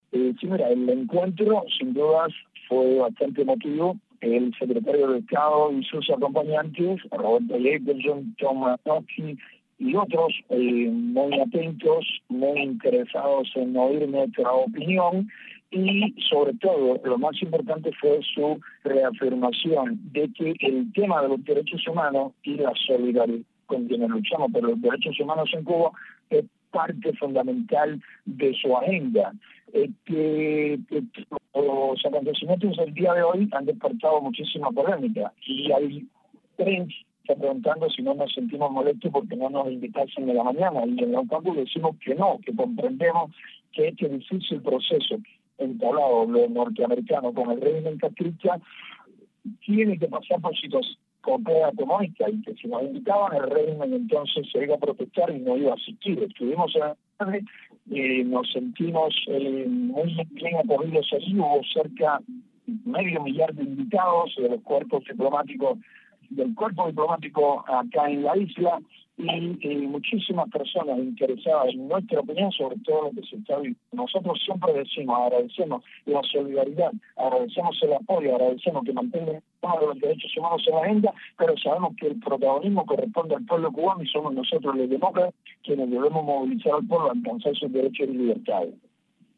Declaraciones de José Daniel Ferrer